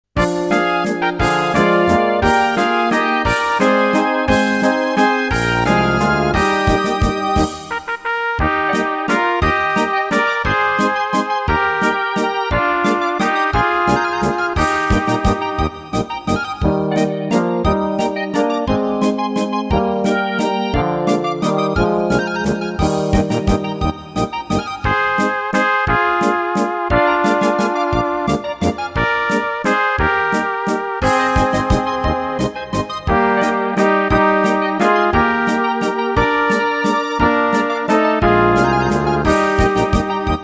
Rubrika: Pop, rock, beat
- valčík